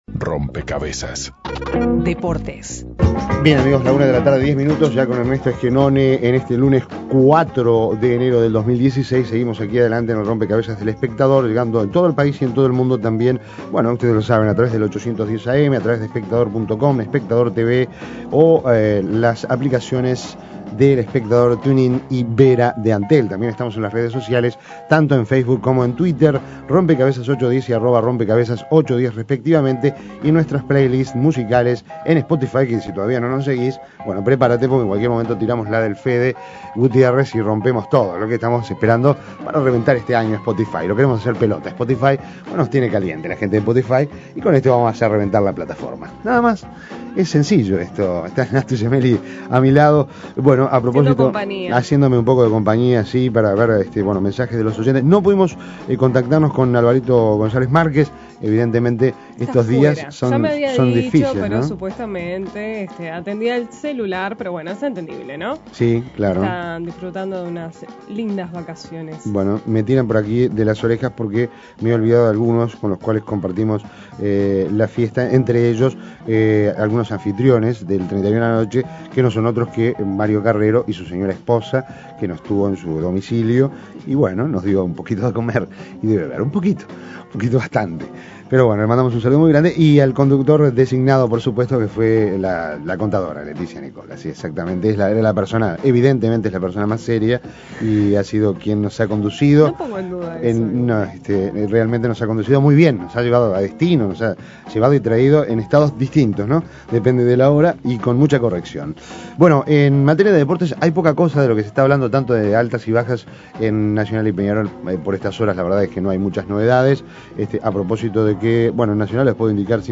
lo entrevistó en 2015 para Biosistemas